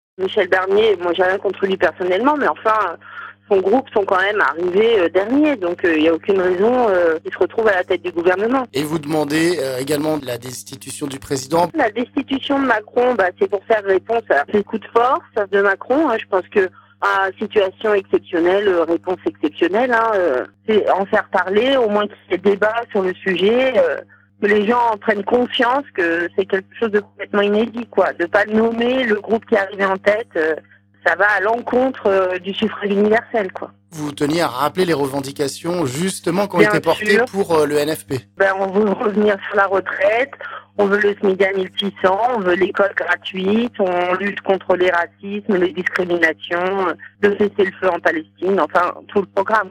On écoute une animatrice d'un groupe local d'actions LFI